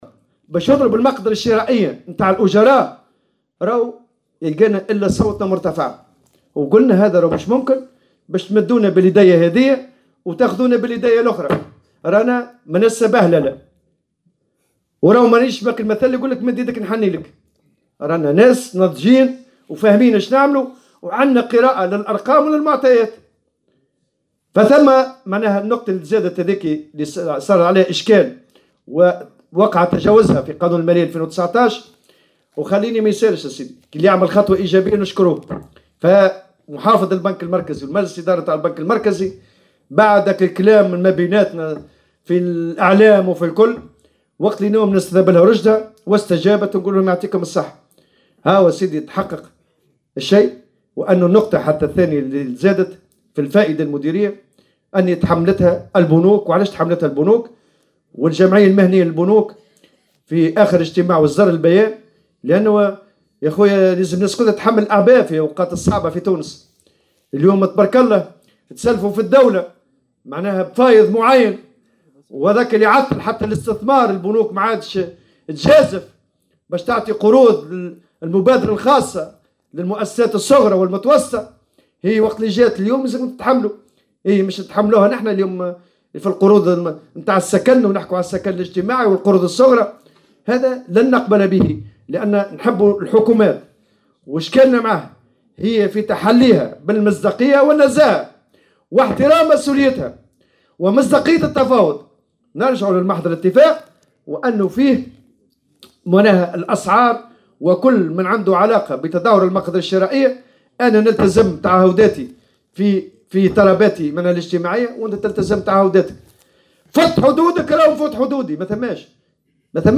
أكد الأمين العام للإتحاد العام التونسي للشغل نور الدين الطبوبي في تصريح لمراسلة الجوهرة "اف ام" اليوم الجمعة أن استجابة البنك المركزي لمطلب حذف النقطتين المضافتين بالنسبة للقروض خطوة تستوجب توجيه الشكر لمن اتخذ القرار بشأنها.